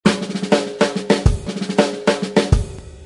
-16 dB RMS dite "Music", c'est à dire, la plus respectueuse du signal et de la dynamique.
Normalisation à -16 dB RMS - Ecoutez
signal_normalise_-16_RMS.mp3